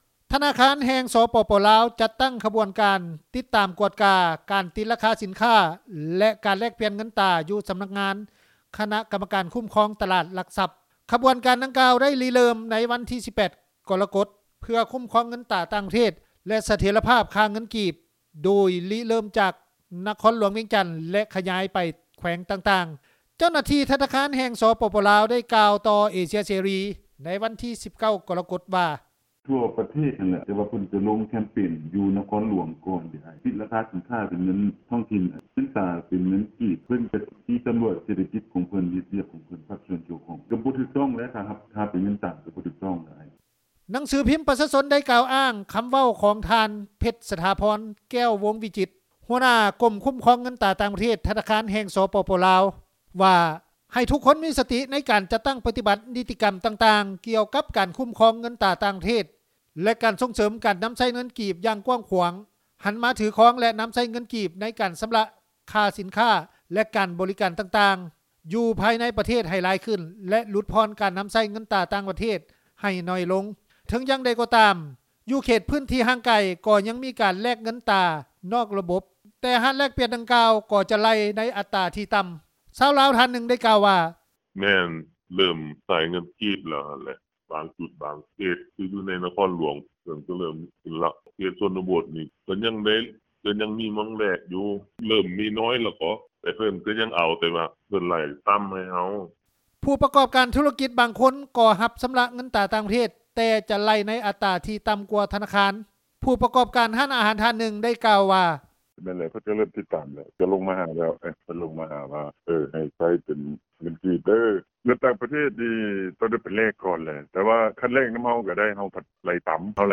ຊາວລາວທ່ານນຶ່ງ ໄດ້ກ່າວວ່າ:
ຜູ້ປະກອບການທຸລະກິດນໍາທ່ຽວ ໄດ້ກ່າວວ່າ: